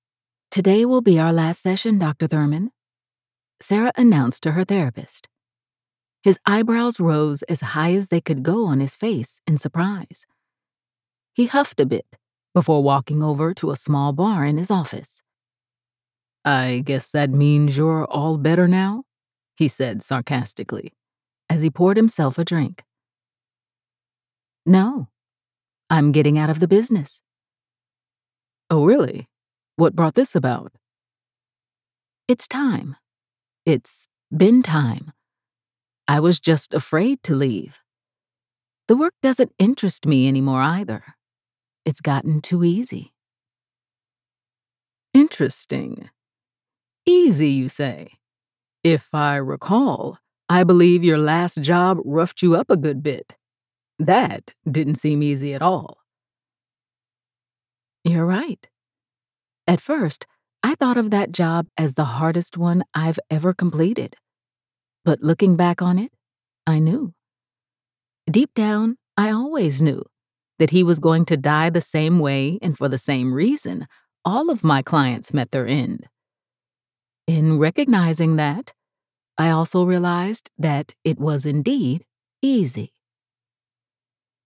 F/M 3rd POV Mystery
My voice is often described as warm and feminine, with a depth that brings a unique richness to every story I narrate.
I believe in delivering high-quality audio, and to achieve this, I use top-notch home studio equipment, including a Double-Walled VocalBooth, Neumann TLM 102, RØDE NT1 5th Gen, Sennheiser 416, and a Focusrite Scarlett 2i2 interface.